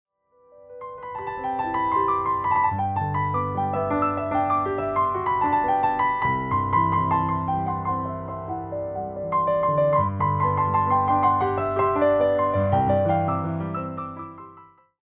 carry gentle rhythmic energy